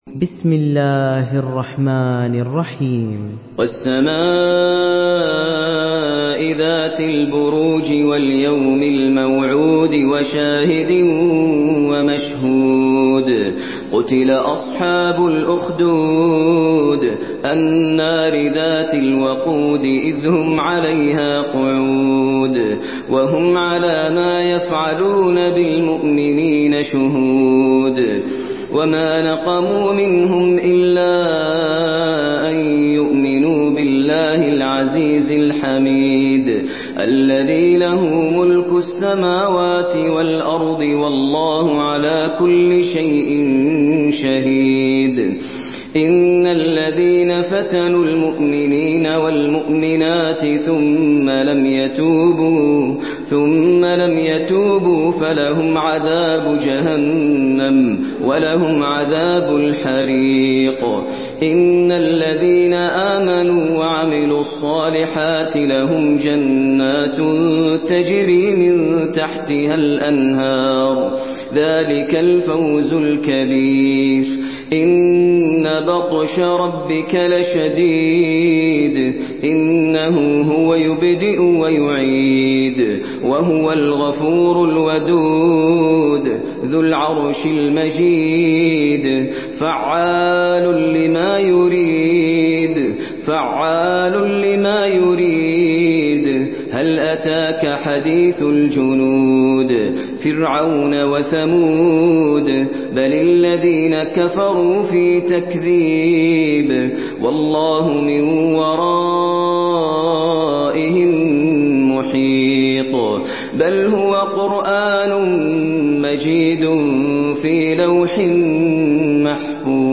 Surah البروج MP3 by ماهر المعيقلي in حفص عن عاصم narration.
مرتل